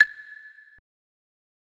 Godzilla Perc 4.wav